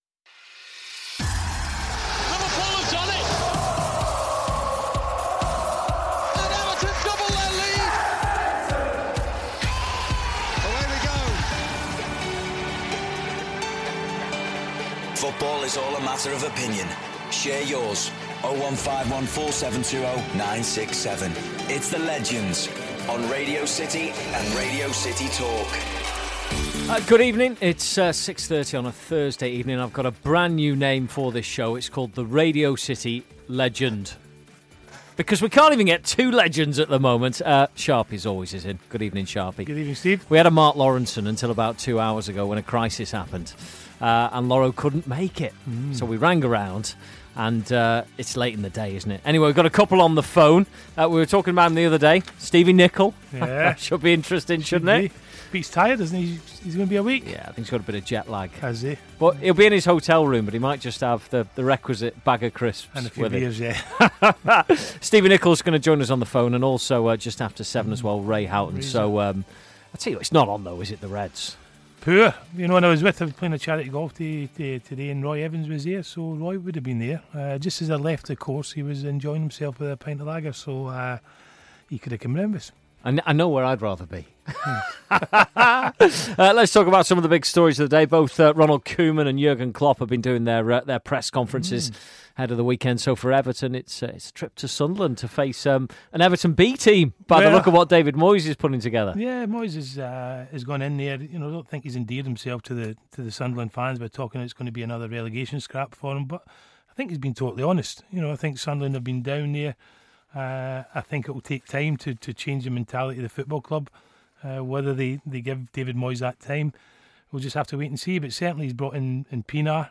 The lads also take your calls.